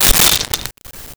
Camera Flash Bulb Crackle 01
Camera Flash Bulb Crackle 01.wav